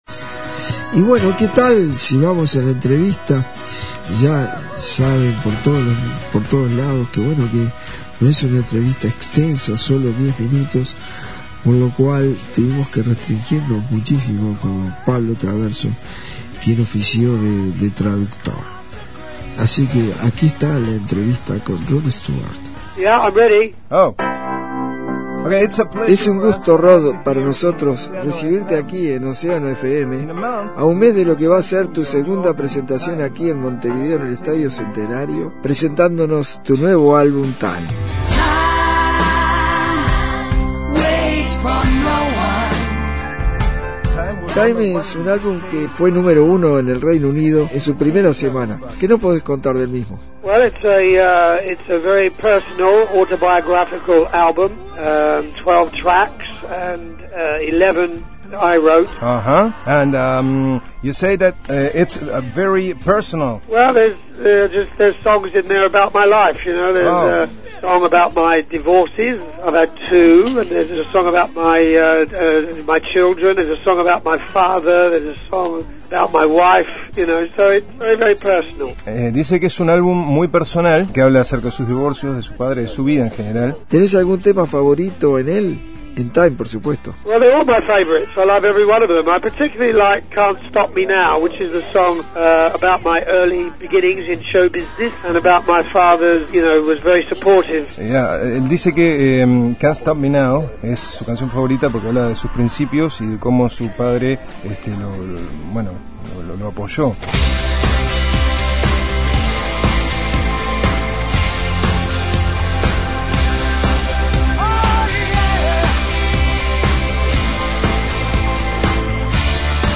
Entrevista a Rod Stewart - Océano